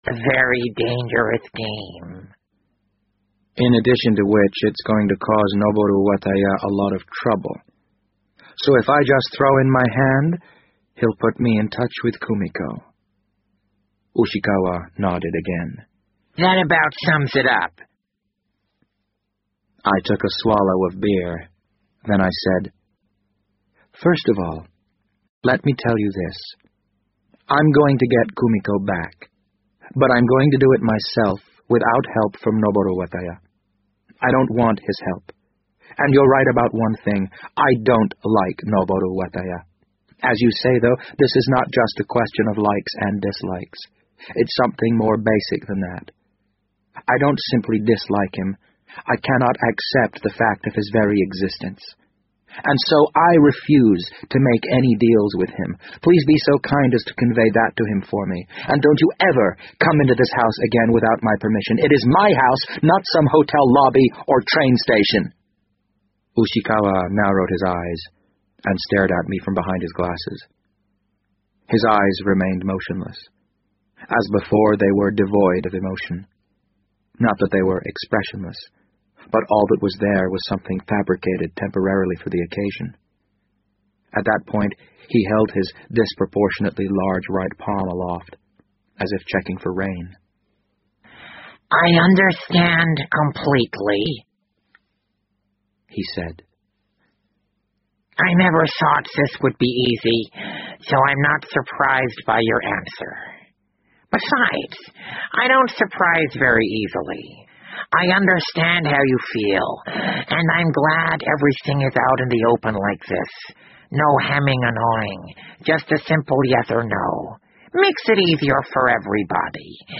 BBC英文广播剧在线听 The Wind Up Bird 011 - 10 听力文件下载—在线英语听力室